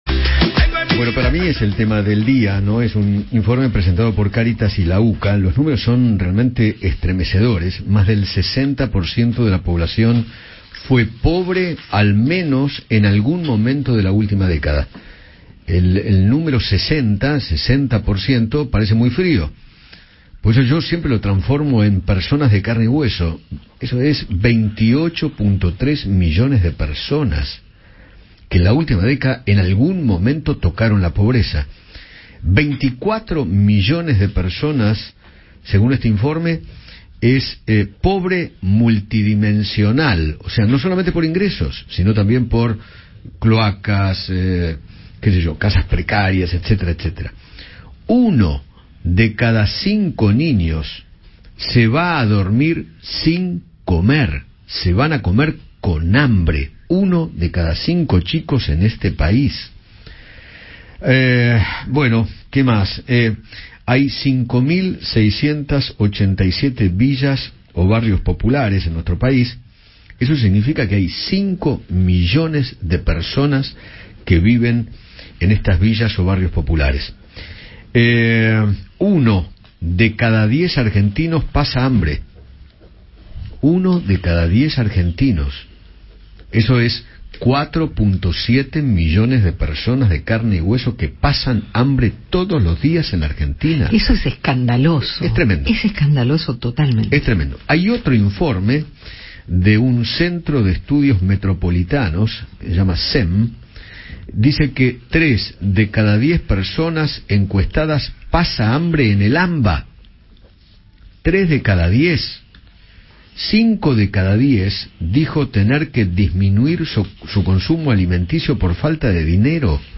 Fernanda Miño, secretaria de Integración Socio Urbana del Ministerio de Desarrollo Social de la Nación, habló con Eduardo Feinmann sobre el nivel de pobreza en la Argentina y se refirió al informe que presentó Cáritas junto a la UCA.